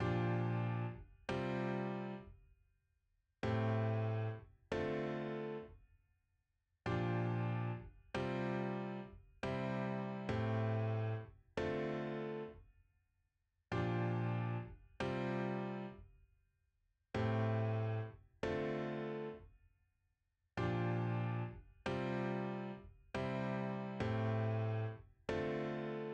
今回はJ-pop1の浮遊感のある感じのコード進行が気に入りましたので、こちらを使用したいと思います。
そのままですとAフラットリディアンで浮遊感もありながら重厚なイメージを感じましたので、今回はもう少し軽い感じにしたかったので、2つキーを上げまして、Bフラットにしています。
ピアノ.wav